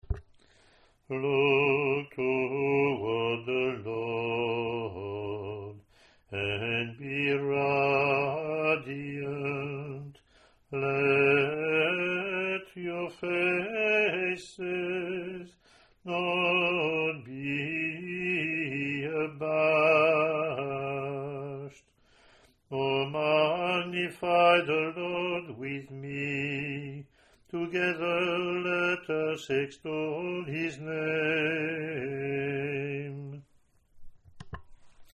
1974 Roman Gradual – Ordinary Form of the Roman Rite
Click to hear Communion (
English antiphon – English verse